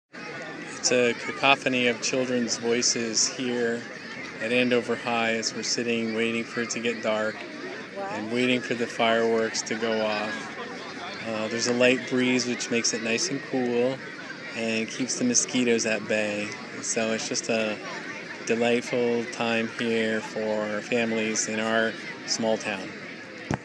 Small Town Fireworks
It's a sweet breezy evening here as we wait in excited anticipation for Independence Day fireworks in our small town of Andover, MA, USA.